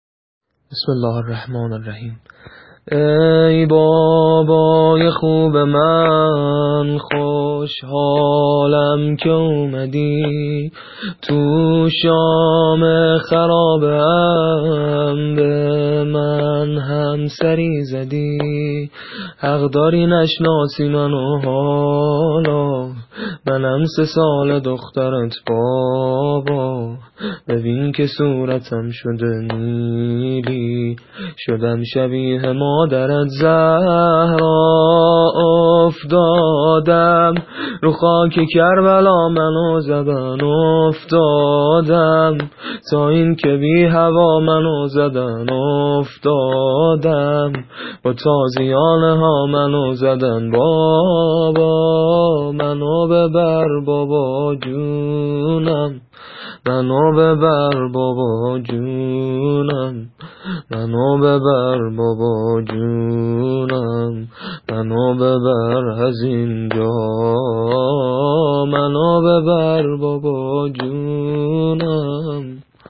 زمینه حضرت رقیه(س)